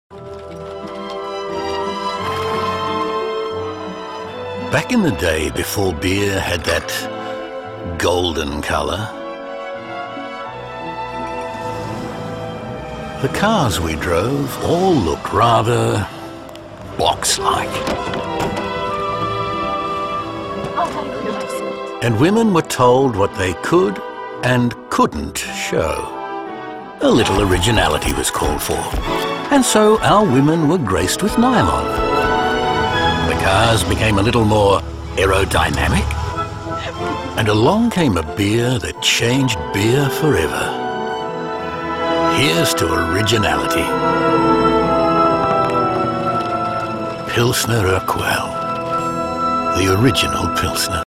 Commercial Demo
Rode NT-1A Microphone, Focusrite interface.
BaritoneDeepLow